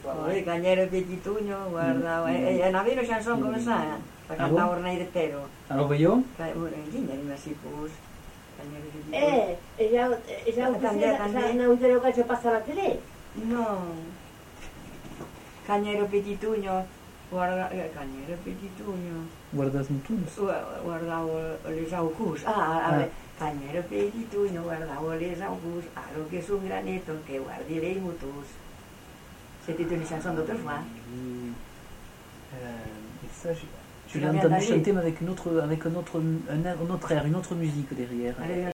Lieu : Ayet (lieu-dit)
Genre : chant
Effectif : 1
Type de voix : voix de femme
Production du son : chanté